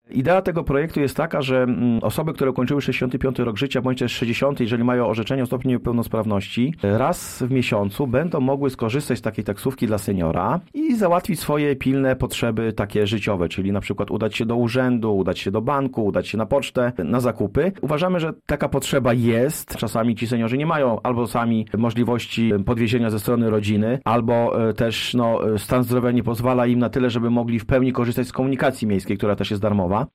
Seniorzy będą mogli bez problemu dotrzeć do urzędu, lekarza czy na cmentarz – mówi prezydent, Paweł Niewiadomski: